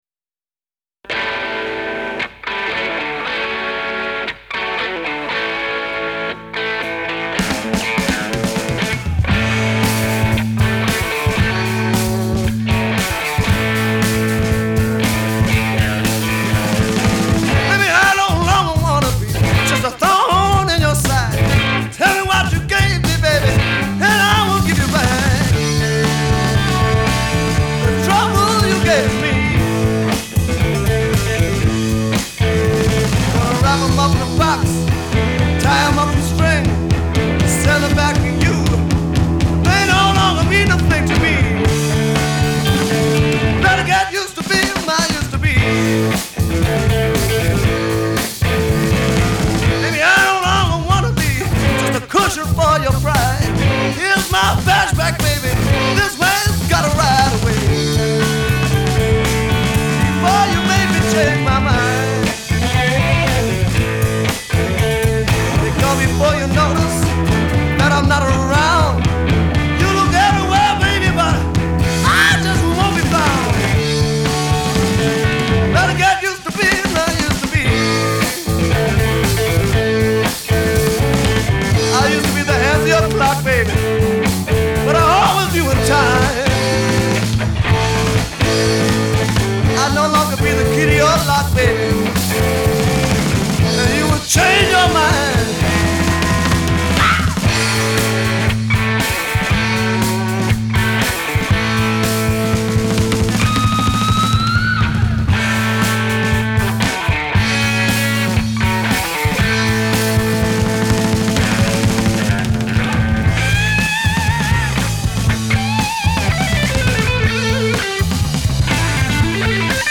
Genre : Blues